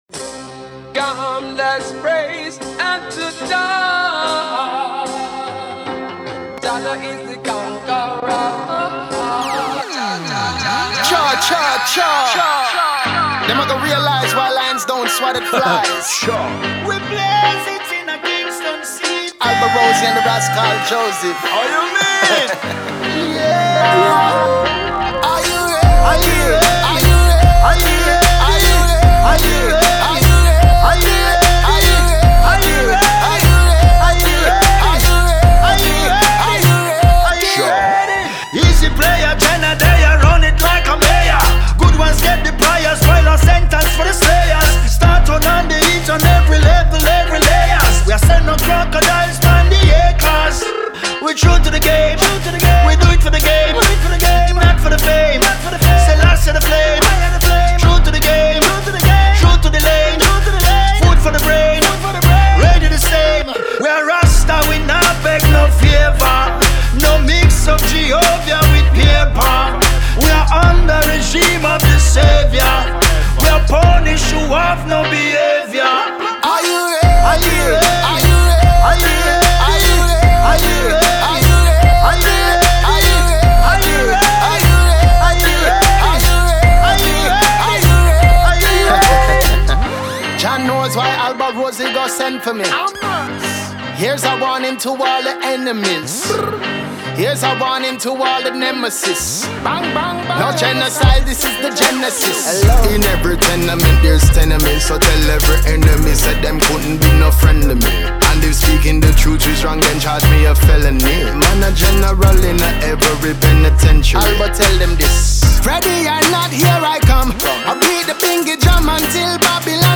Roots Reggae